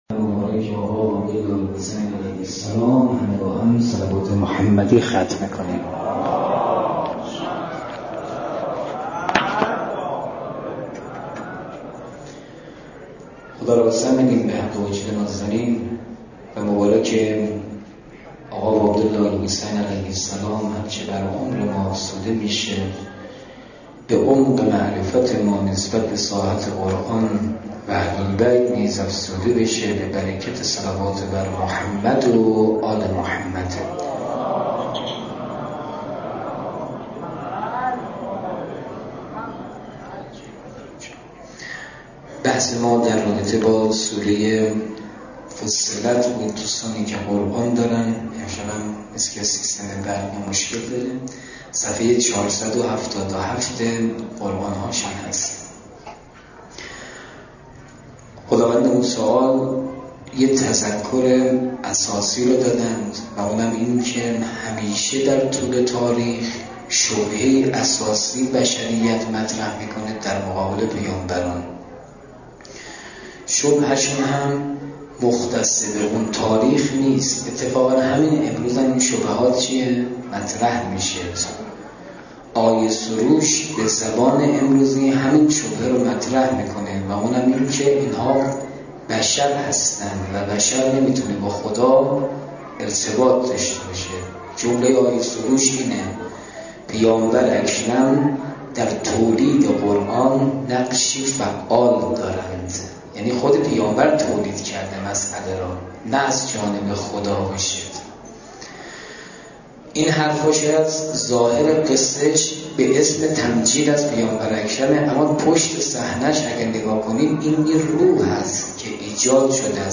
تفسیر سوره فصلت - محرم 1395 - هیئت حضرت علی اصغر(ع) - جلسه سوم